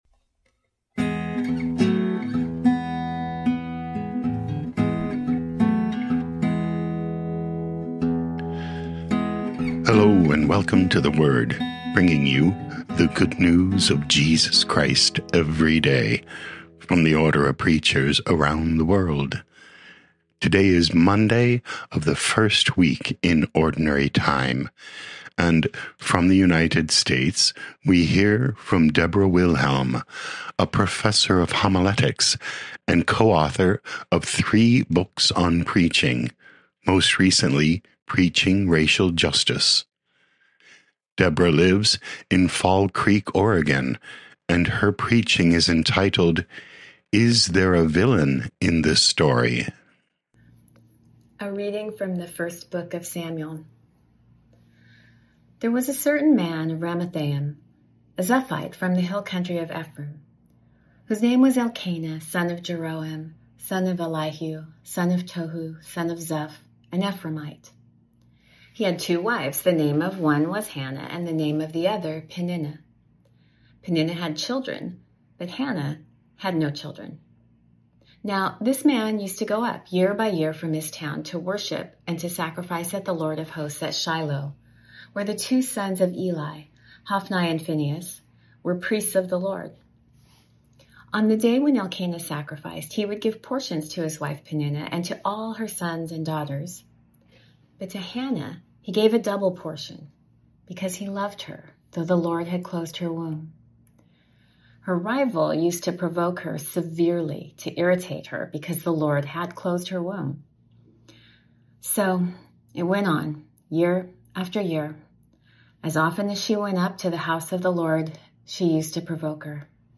Preaching